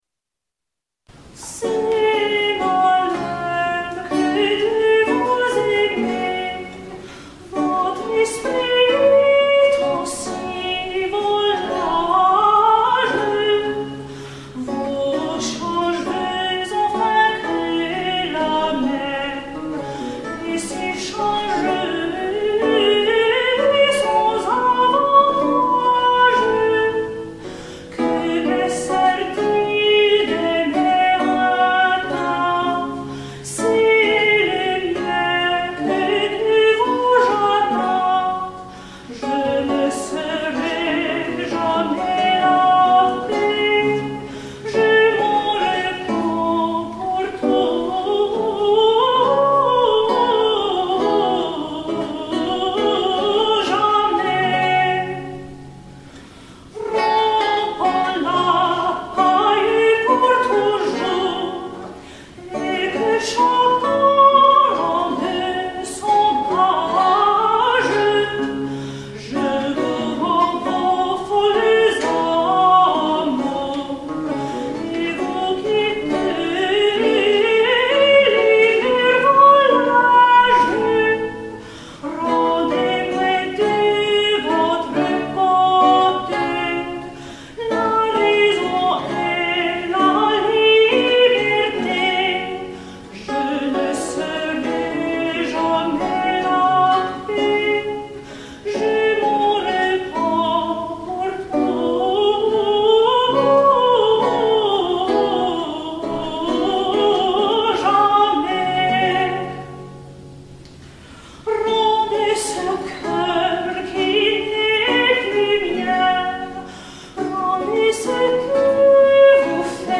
air de cour